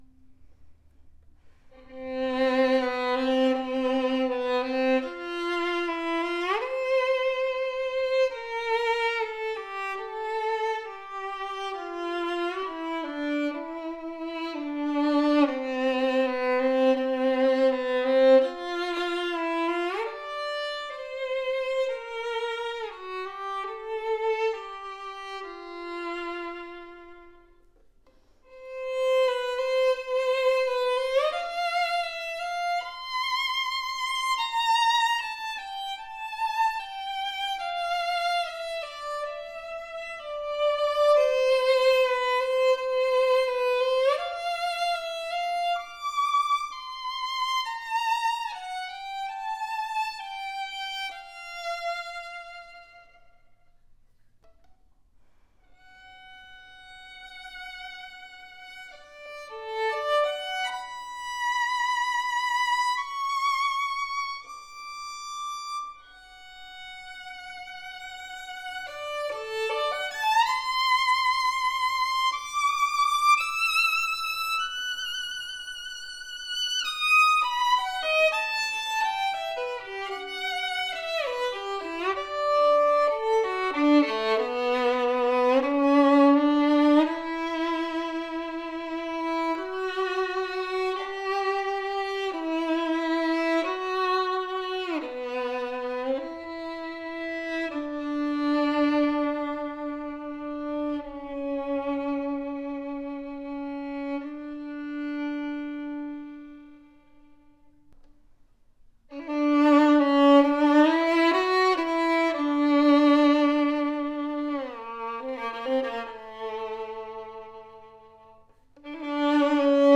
• Best academic instrument in the market guarantee, superb resonant tone and pro playability!
A projective sounding Cannone violin with deep voice, bold lower register, powerful tone with clear tone!
Full resonant G string as the typical Guarneri voice, open voice with a booming texture. Sweet even mid range. Brilliant rounded E string as the performance represents.